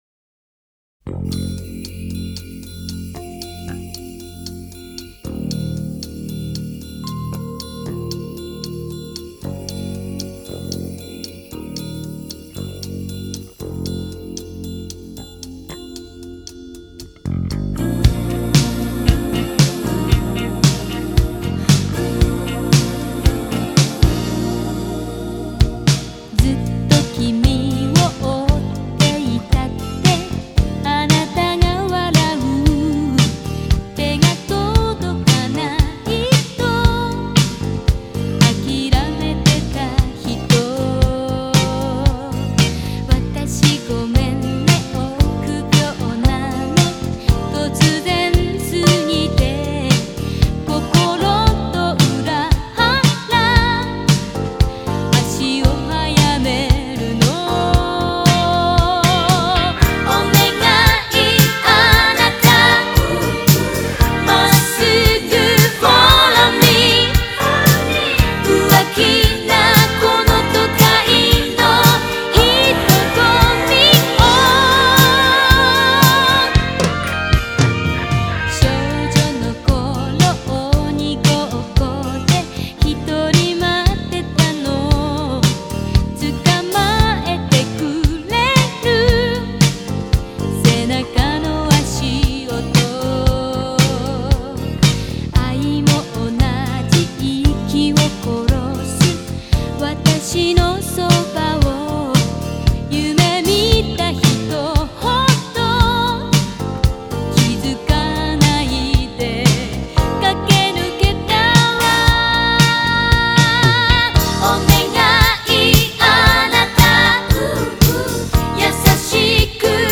Обладает красивым и проникновенным высоким голосом.
Жанр: J-Pop, Ballad